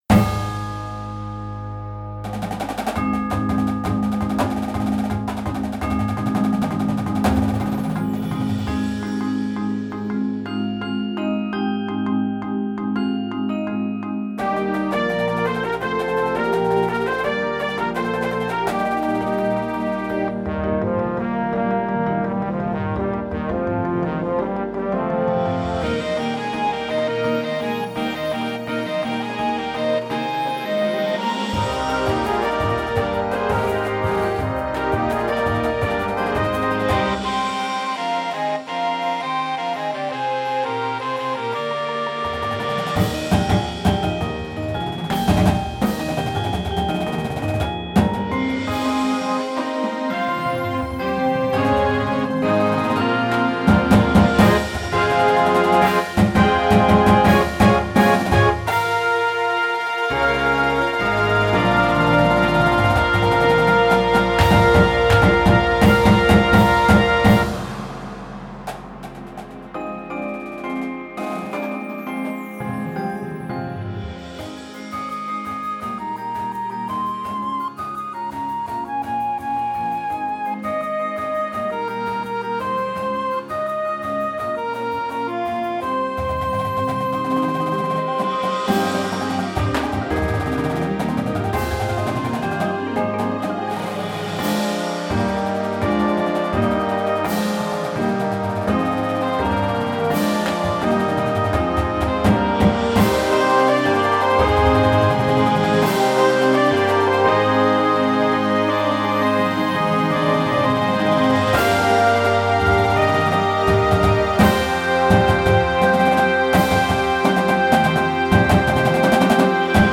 Instrumentation: Winds and Full Percussion